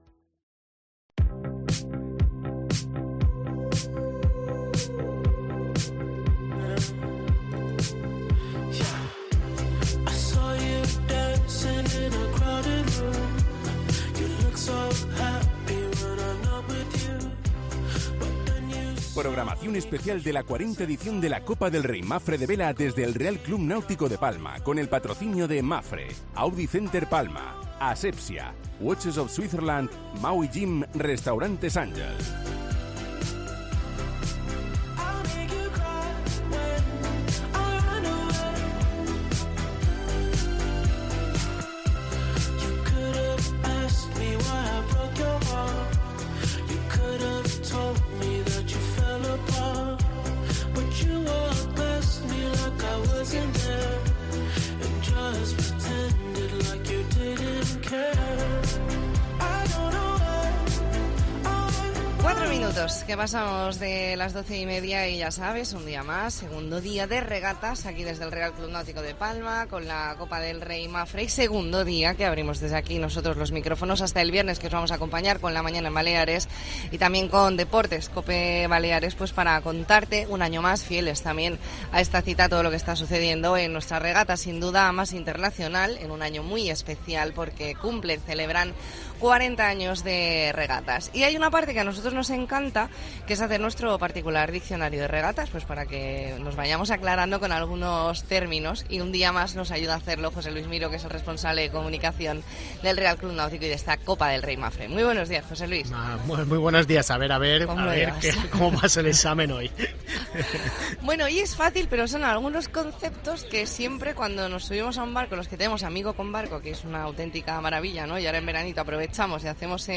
AUDIO: Especial La Mañana en COPE Más Mallorca desde el RCNP con motivo de la 40 Copa del Rey Mapfre
Entrevista en La Mañana en COPE Más Mallorca, martes 2 de agosto de 2022.